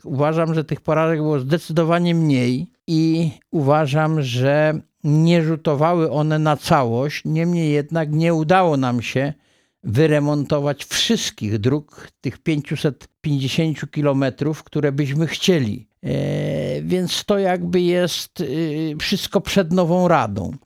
W studiu Radia Rodzina gościliśmy dziś Wiesława Zająca, który podsumował VI kadencję Rady Powiatu Wrocławskiego, opowiedział o perspektywach na kolejną, VII kadencję.